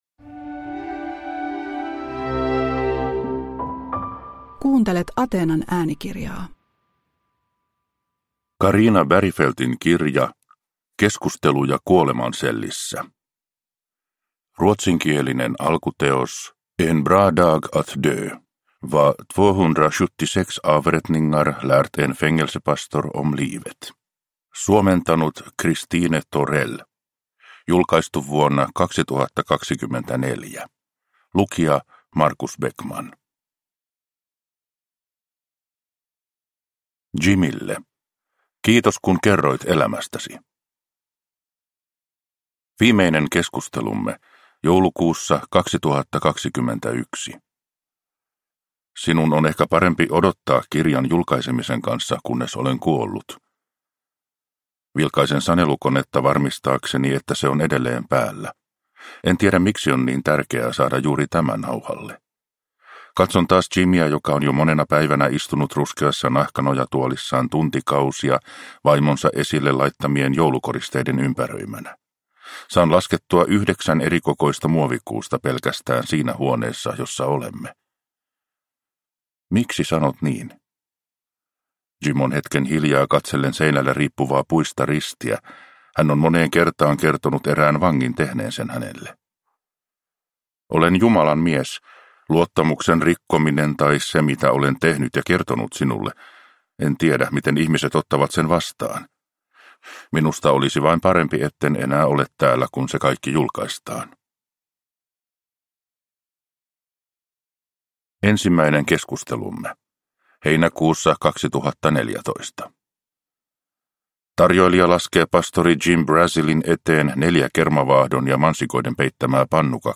Keskusteluja kuolemansellissä (ljudbok) av Carina Bergfeldt